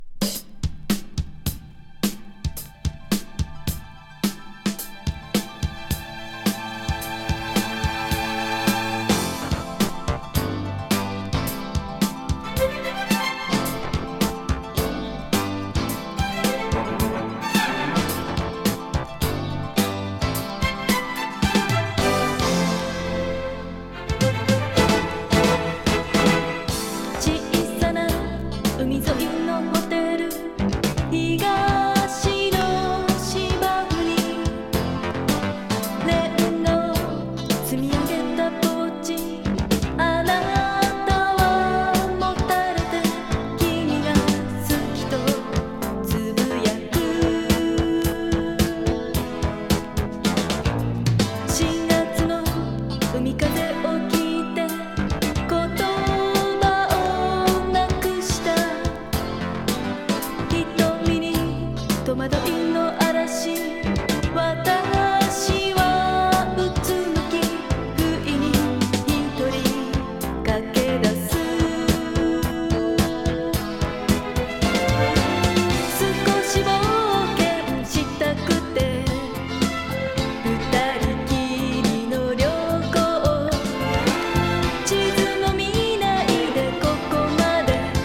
はイントロドラムブレイクから始まるCity Pop！サビの爽快感が最高。